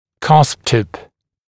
[kʌsp tɪp][касп тип]верхушка бугра